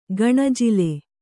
♪ gaṇajile